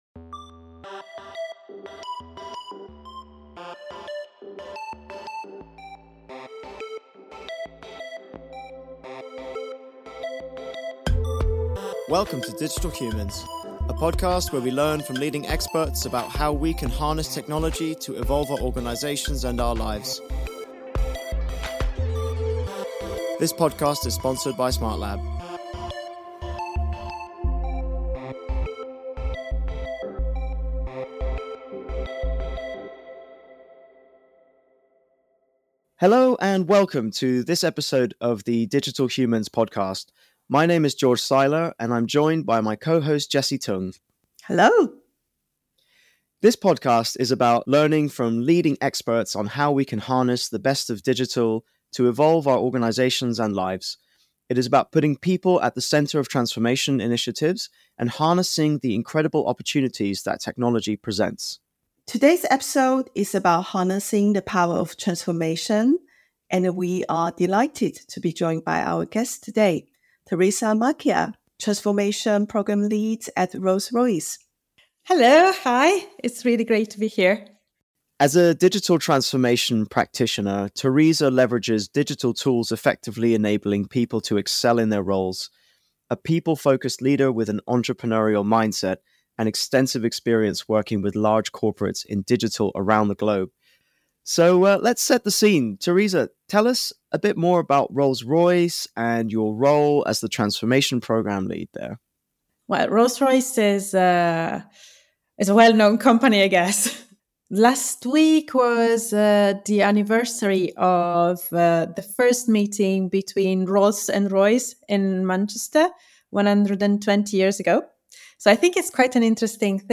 Don’t miss this engaging and informative conversation filled with practical advice and expert perspectives on thriving in a digitally transformed world.